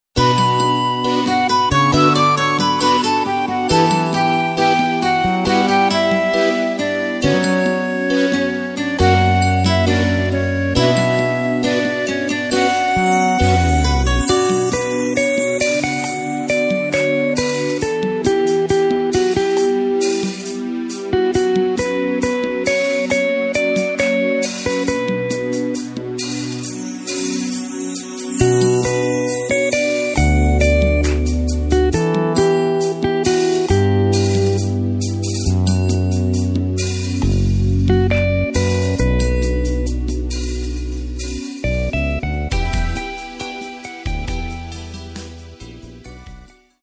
Takt:          4/4
Tempo:         136.00
Tonart:            Bm
Austropop aus dem Jahr 1989!